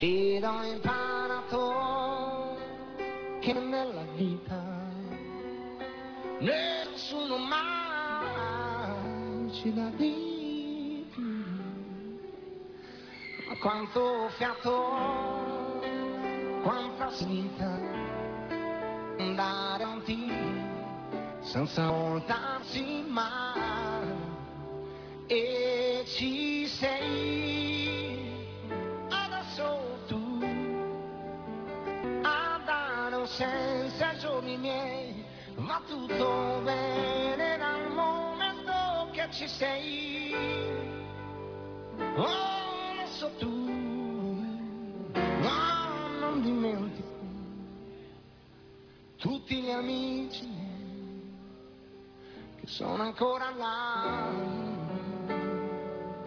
(show de Munich)